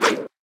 brush2.ogg